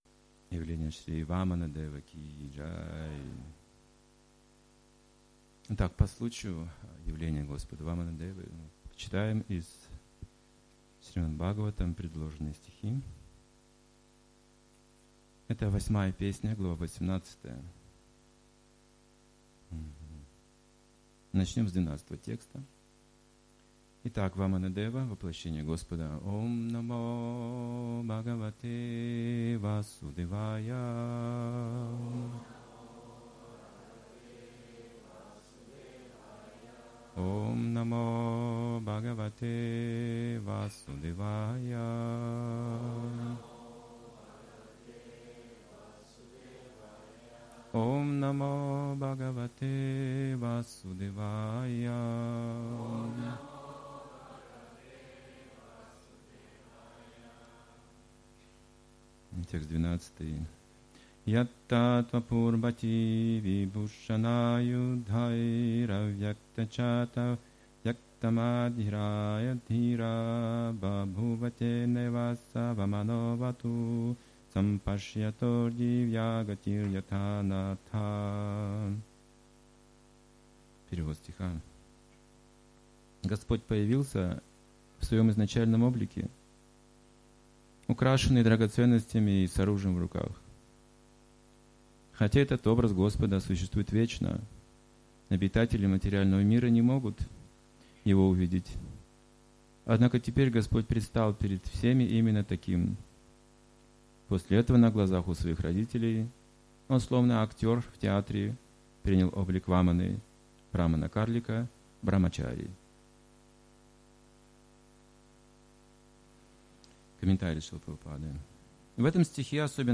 Лекция по стиху из Священного Писания Шримад-Бхагаватам (Ш.Б. 8.18.12) о воплощении Господа в облике Ваманадева, брахмана-карлика. О великих святых в вайшнавской традиции.